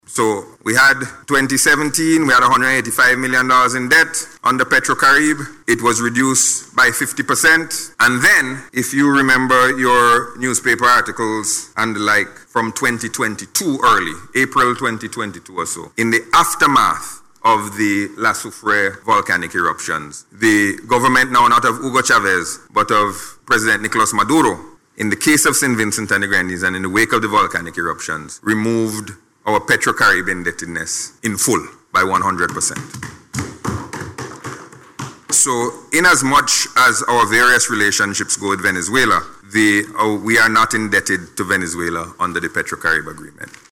Speaking in Parliament in response to a question about the status of the programme, Gonsalves stated that the country is not indebted to Venezuela under the energy cooperation framework.